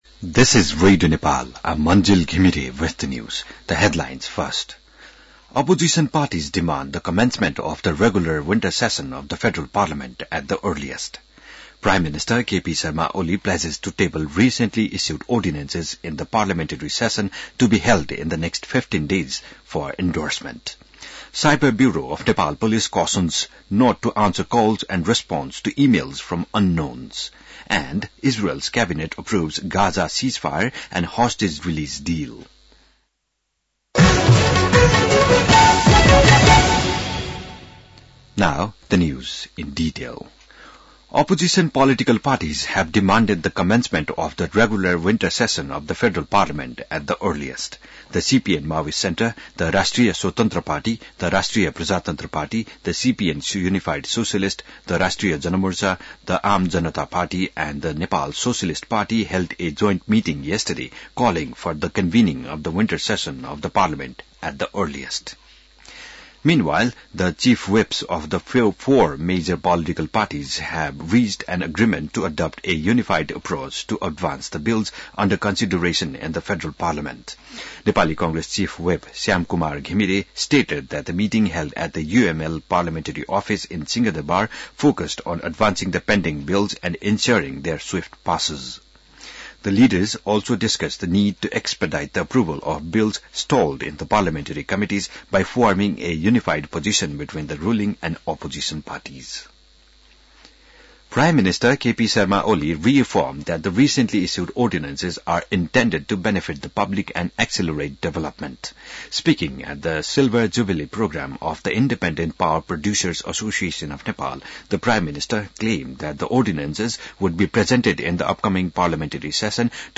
बिहान ८ बजेको अङ्ग्रेजी समाचार : ६ माघ , २०८१